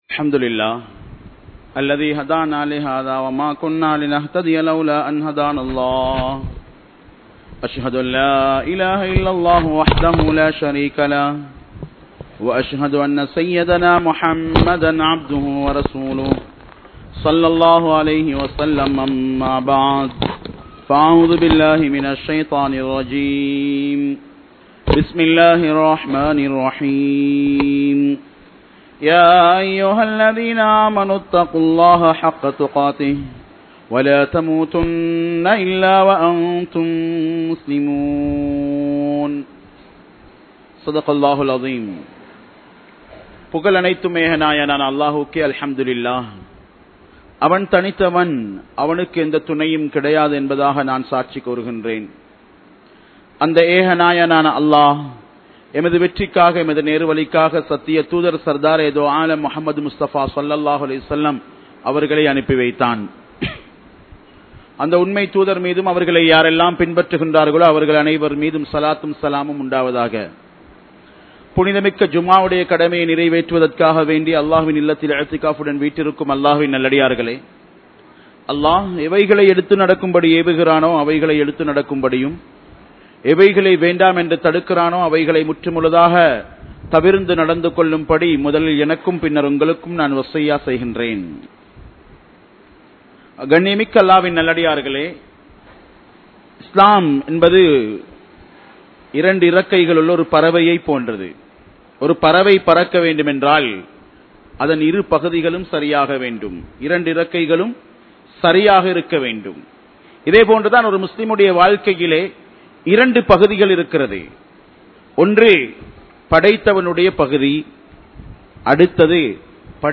Pettroarhalukku Thuroaham Seiyatheerhal (பெற்றோர்களுக்கு துரோகம் செய்யாதீர்கள்) | Audio Bayans | All Ceylon Muslim Youth Community | Addalaichenai